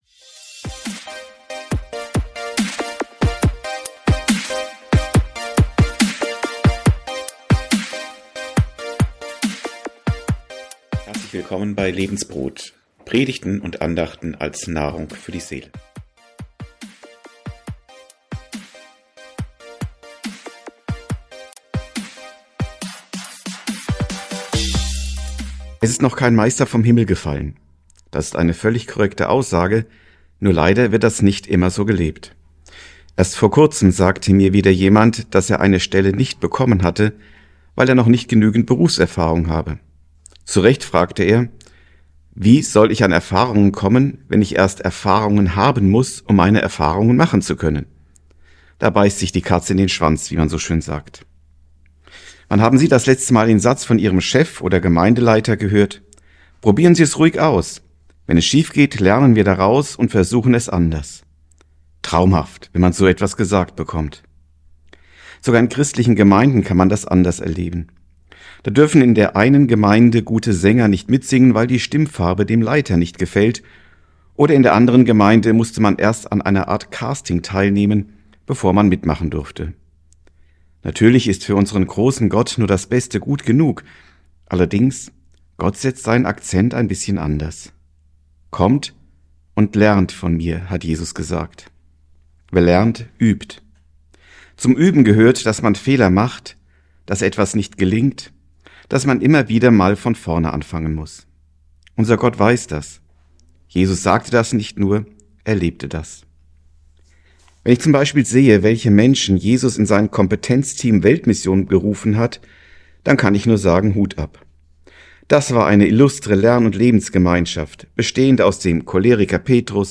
Learning by doing ~ Predigten u. Andachten (Live und Studioaufnahmen ERF) Podcast